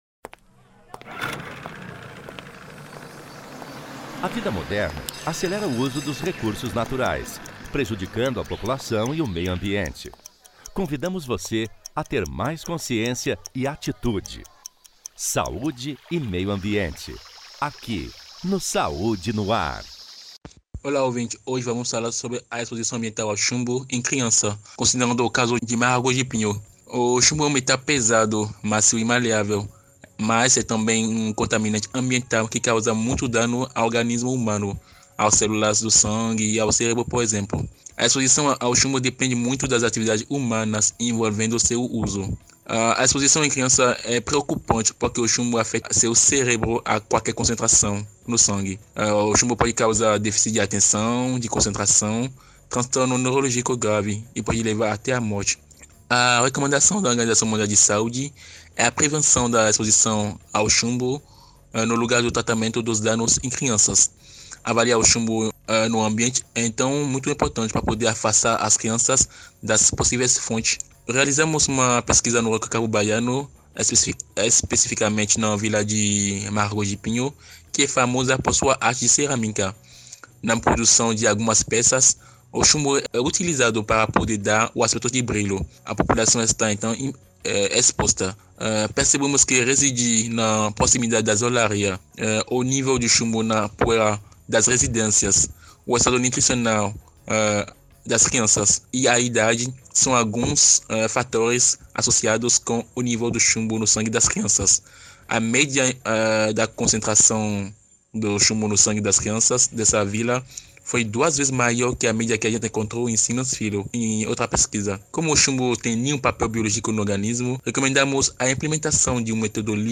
O assunto foi tema do quadro “Meio Ambiente e Saúde”, veiculado às quartas-feiras pelo programa Saúde no Ar. na Am 840  e Rádio Web Saúe.